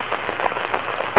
static3.mp3